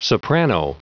Prononciation du mot soprano en anglais (fichier audio)
Prononciation du mot : soprano